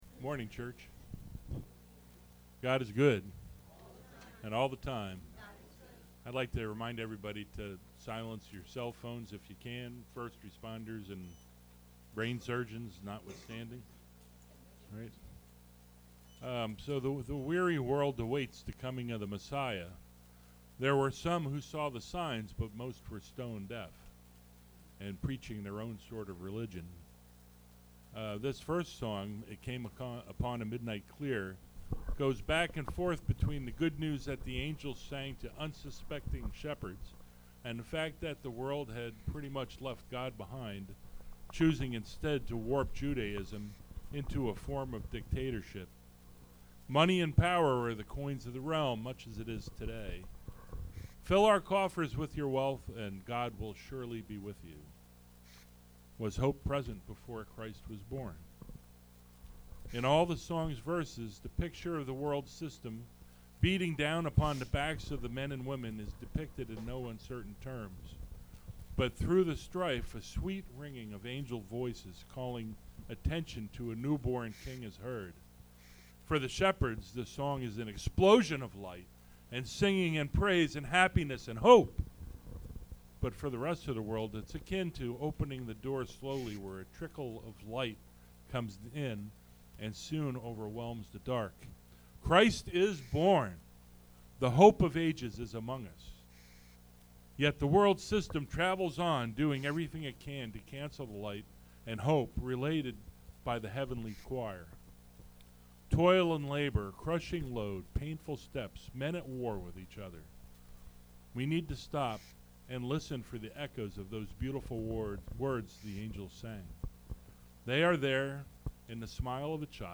Christmas Cantata
Service Type: Sunday Morning Worship Service